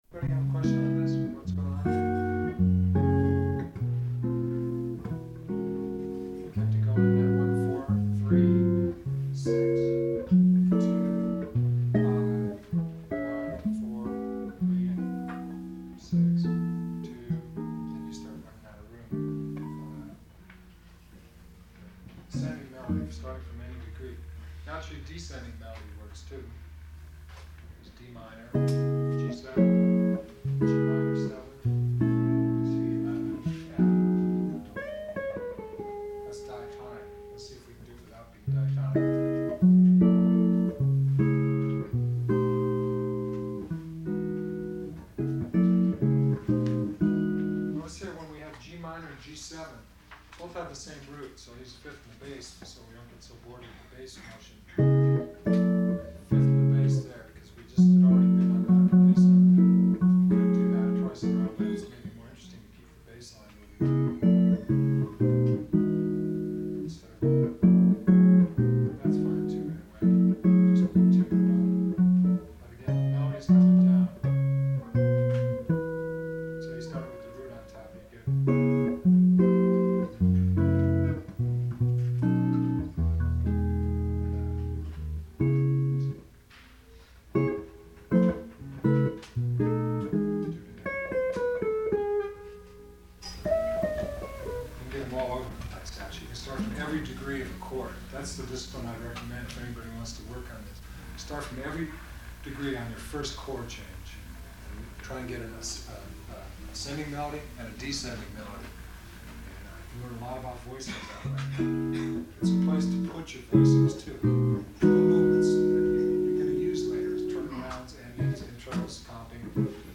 Ted Greene "Guitar Institute of Technology" Seminar - 1978